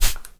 spray_bottle_09.wav